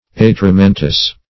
Search Result for " atramentous" : The Collaborative International Dictionary of English v.0.48: Atramental \At`ra*men"tal\, Atramentous \At`ra*men"tous\, a. Of or pertaining to ink; inky; black, like ink; as, atramental galls; atramentous spots.